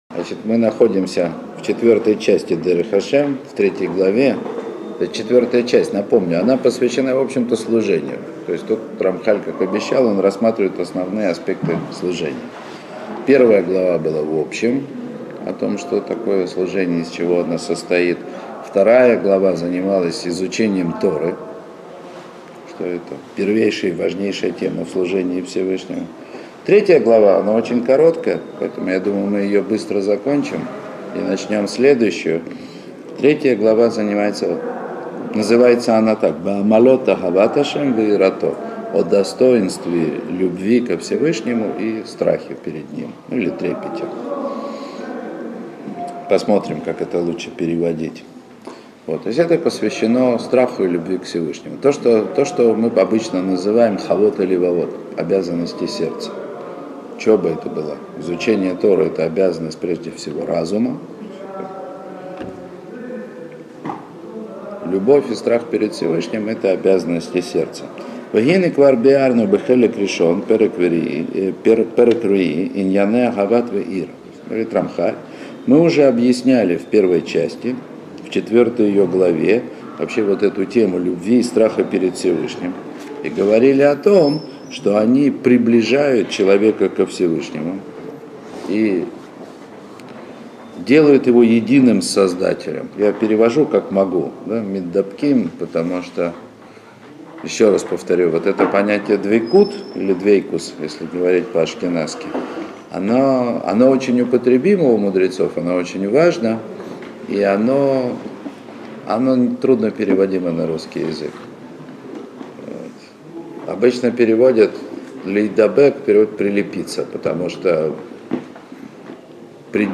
Урок 61. О любви ко Всевышнему и страхе перед Ним.